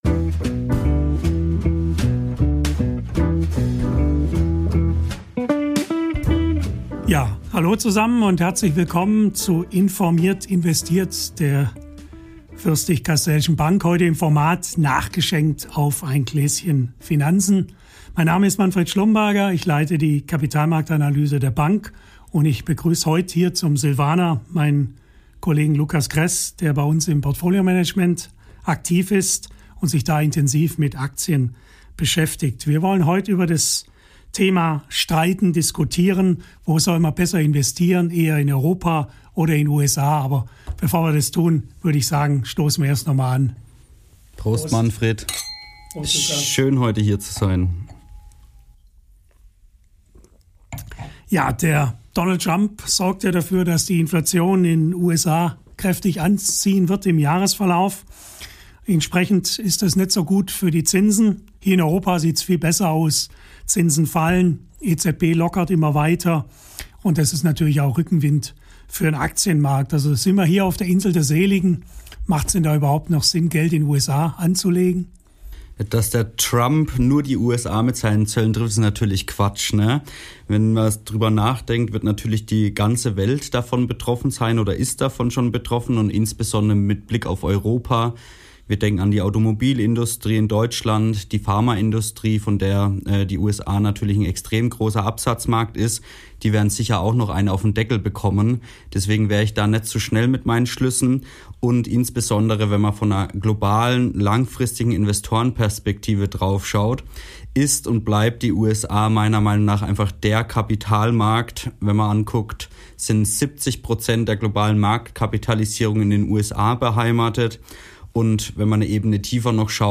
Ehrlich, humorvoll und natürlich mit einem Gläschen Wein.